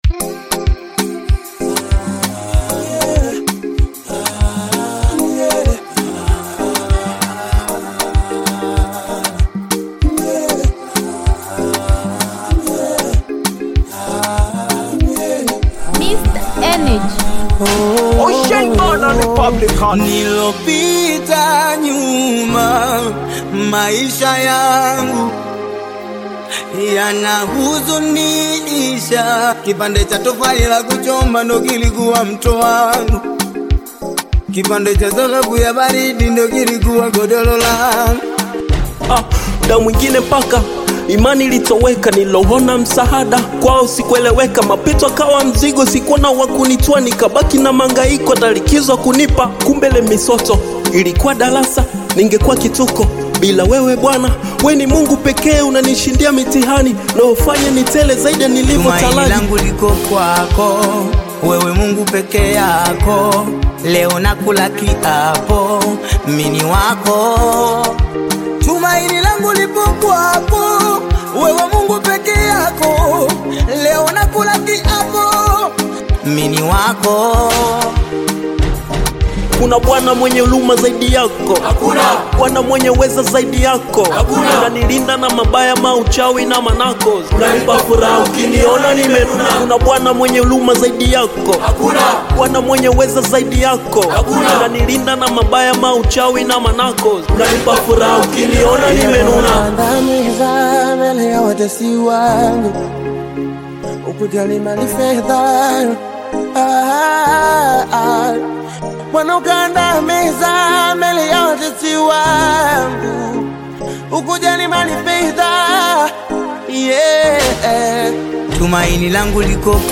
Tanzanian gospel single
uplifting Swahili worship lyrics
soulful vocal delivery and heartfelt gospel sound